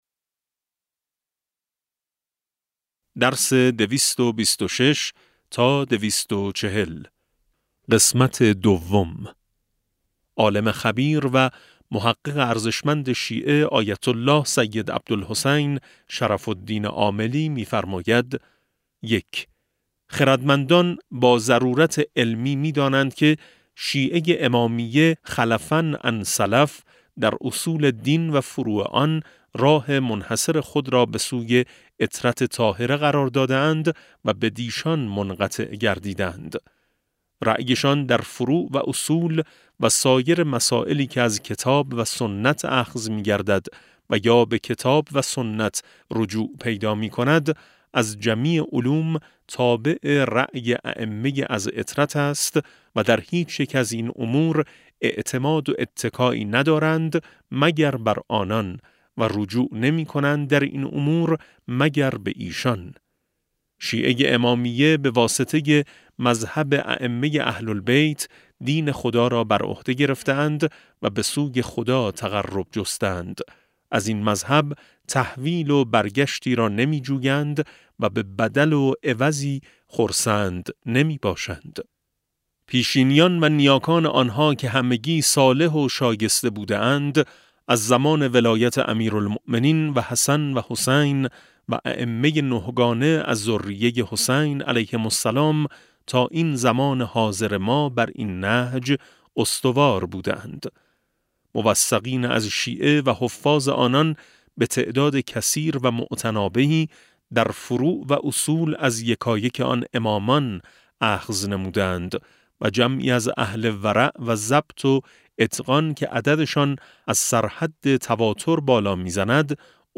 کتاب صوتی امام شناسی ج 16 و17 - جلسه2